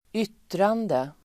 Uttal: [²'yt:rande]